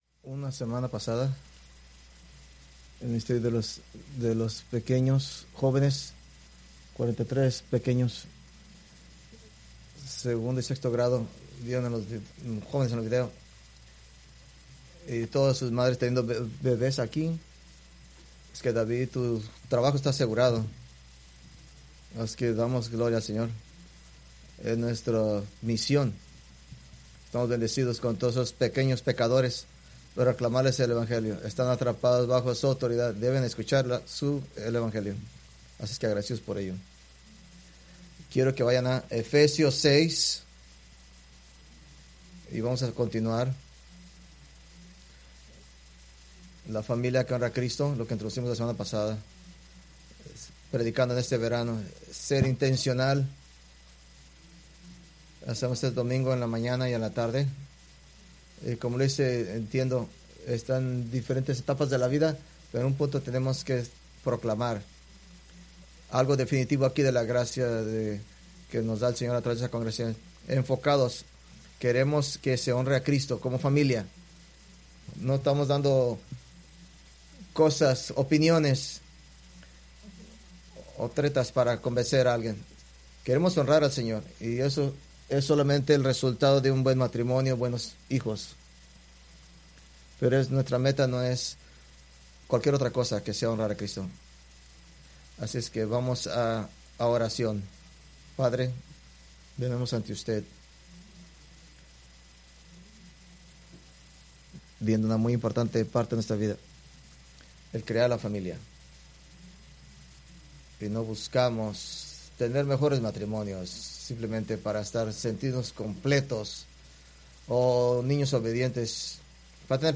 Preached July 6, 2025 from Escrituras seleccionadas